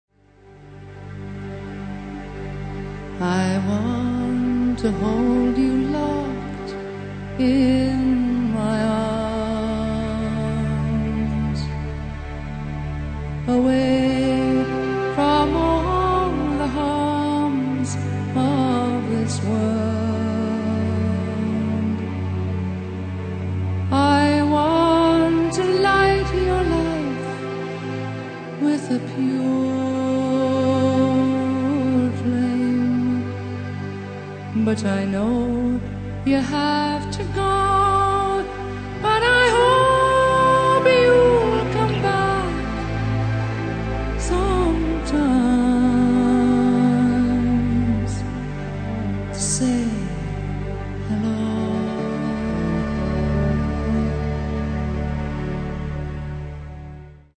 First part, 0:56 sec, mono, 22 Khz, file size: 281 Kb.